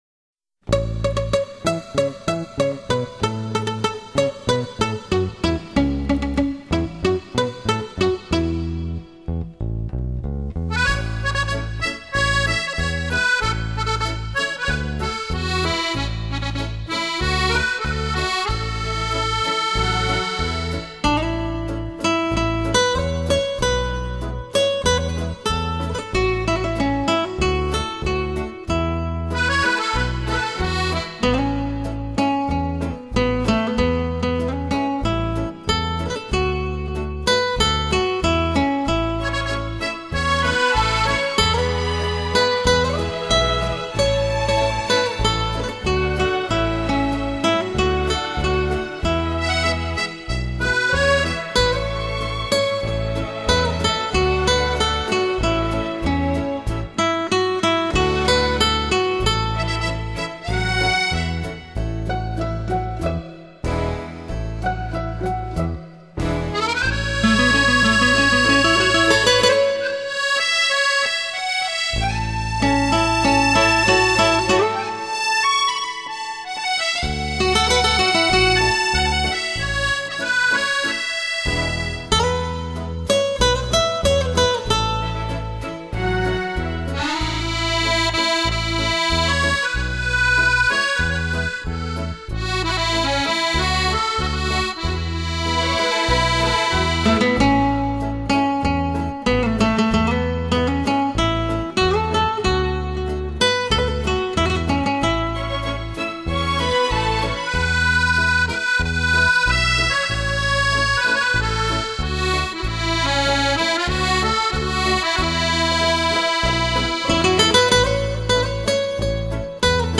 他的彈奏風格淳厚溫和，爐火純青。
十九首經典金曲，首首動聽，旋律之優美，使人再三回味。